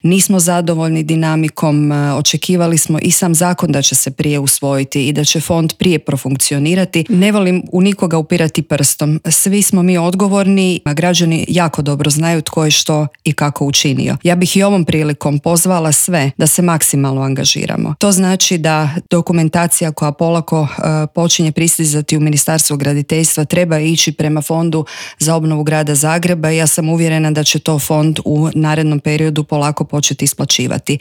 ZAGREB - Uoči lokalnih izbora u razgovoru za Media servis zamjenica gradonačelnika te kandidatkinja Bandić Milan 365 Stranke rada i solidarnosti za zagrebačku gradonačelnicu Jelena Pavičić Vukičević progovorila je o obnovi Zagreba od potresa kao i kakve planove ima s Dinamovim stadionom.